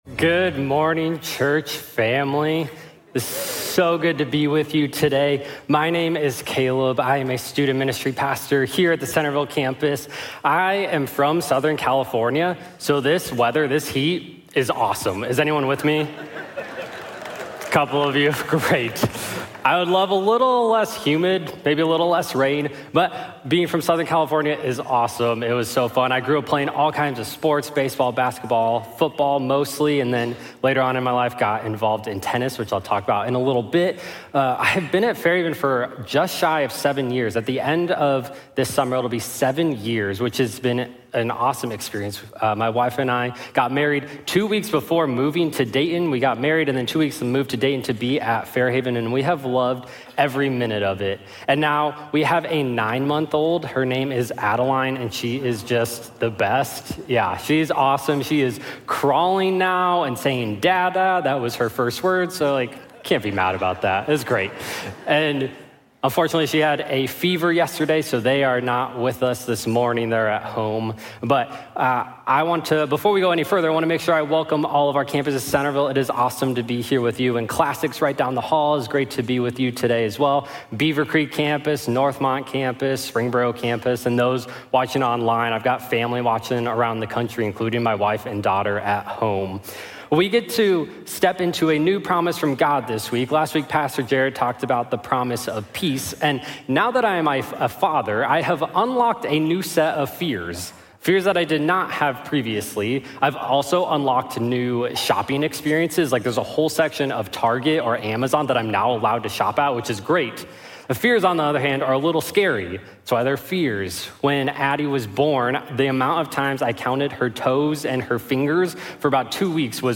God-is-With-You_SERMON.mp3